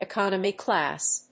アクセントecónomy clàss